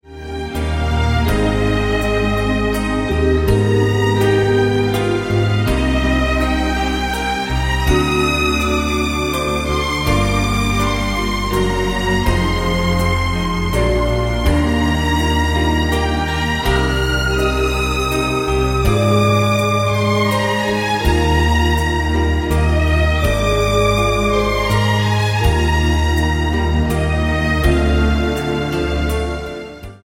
Genre: Classical
Heavy Weight 180 gram LP Audiophile Recording!
filled with familiar, romantic numbers
Arrangements are lush, and delivered passionately.